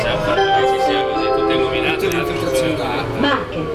sottomilano ha scattato per voi alcune immagini delle stazioni e dei treni, girato alcuni video e registrato gli annunci sonori sui treni.
Annunci sonori
qua per l'annuncio di "Fermata attuale" (file .WAV).
fermata_marche.wav